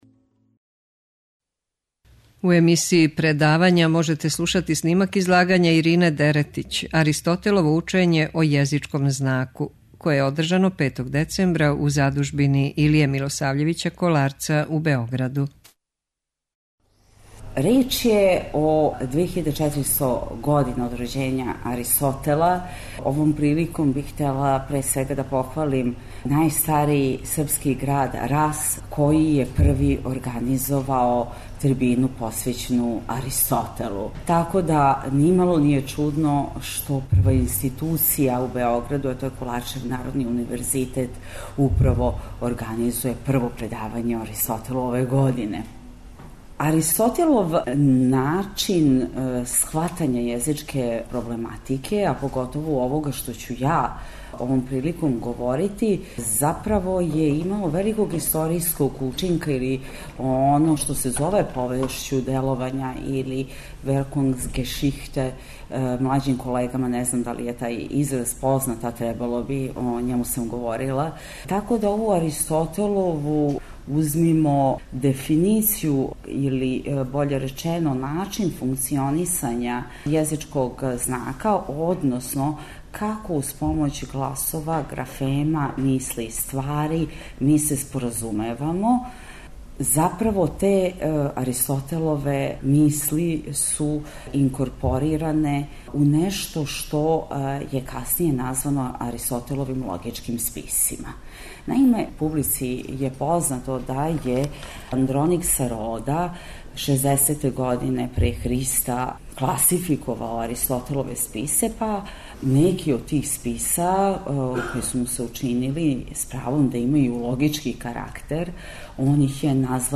Предавања